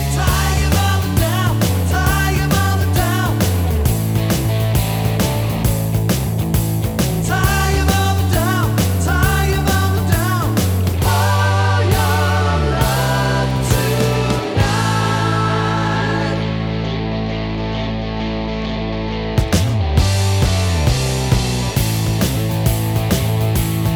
Minus Lead Guitar Rock 3:45 Buy £1.50